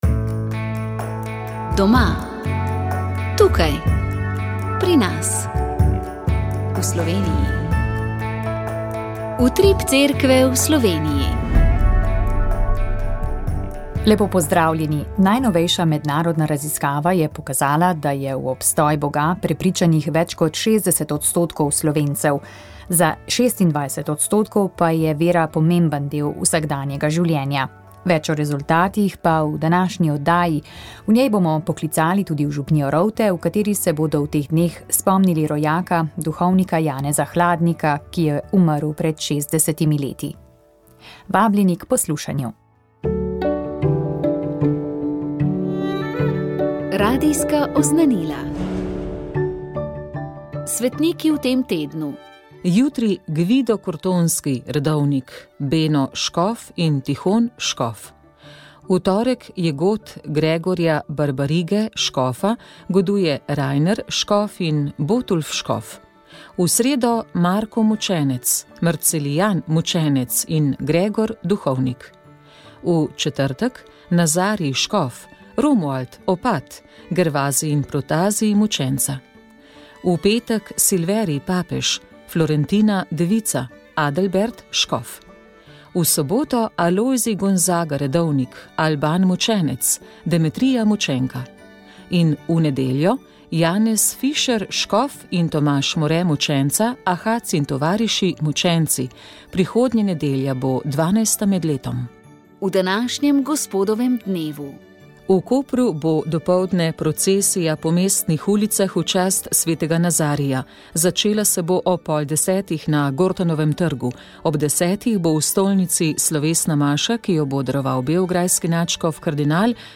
V Sobotnem duhovnem večeru ste najprej slišali duhovni nagovor, ki ga je pripravil ob prazniku Svete Trojice nadškof Marjan Turnšek. Sledile so prve praznične večernice. Nadaljevali smo s petimi litanijami Srca Jezusovega, s katerimi smo prosili za mir na svetu.